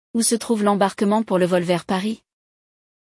Esse episódio traz um diálogo prático entre um passageiro e um atendente de aeroporto, mostrando como usar vocabulário essencial para essa situação.
Além de ouvir o diálogo real, você será guiado por explicações detalhadas sobre as palavras e expressões utilizadas.